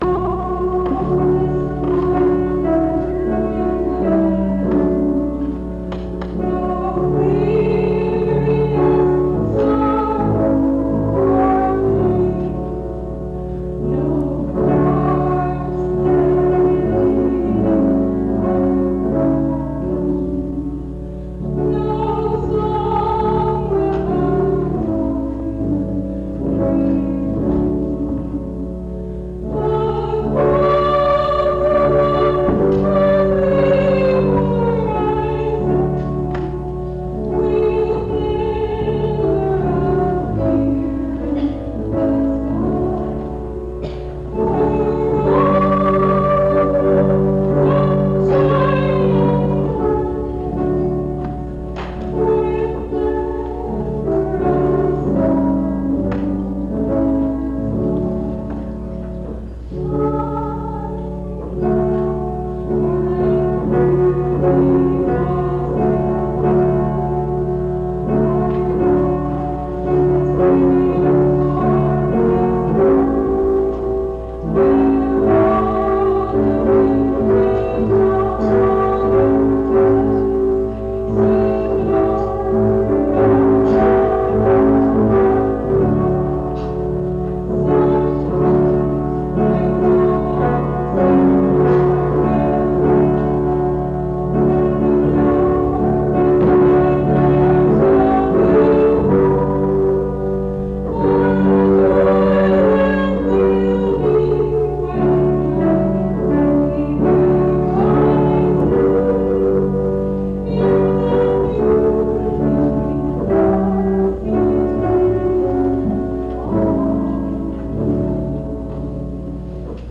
This recording is from the Monongalia Tri-District Sing.